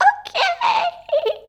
OKAYY.wav